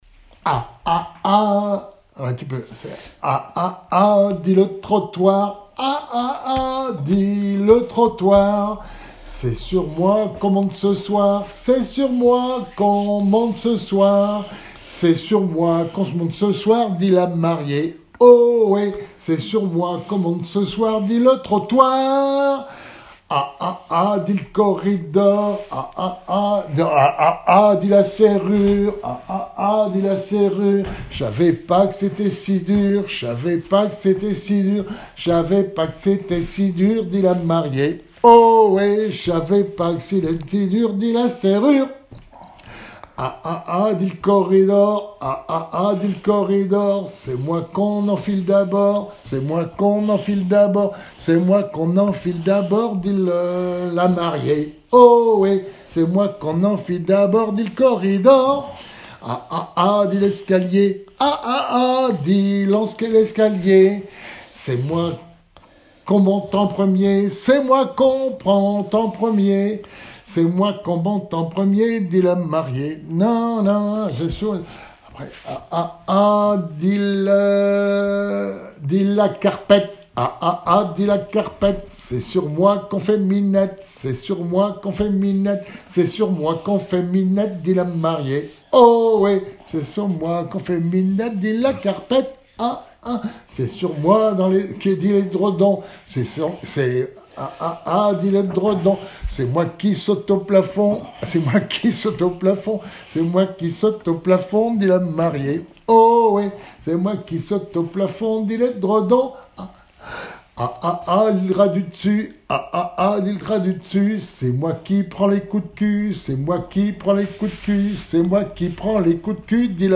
Genre énumérative
Témoignages et chansons
Pièce musicale inédite